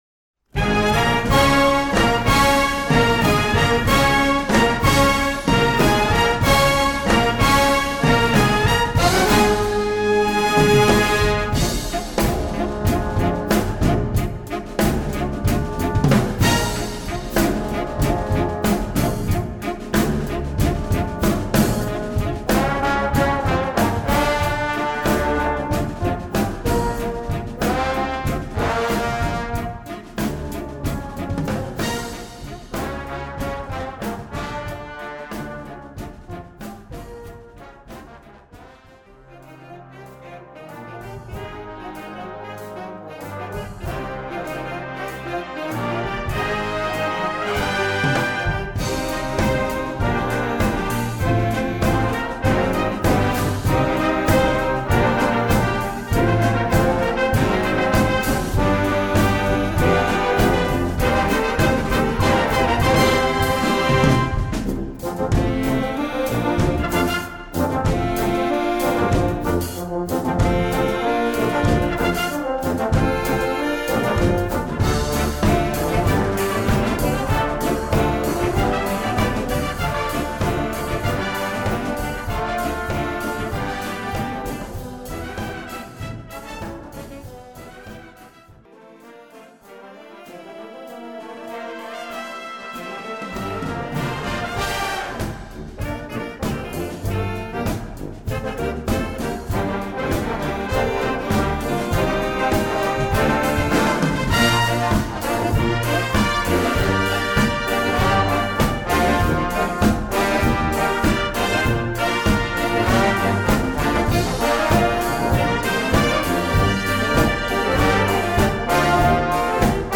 Blaasorkest
Demo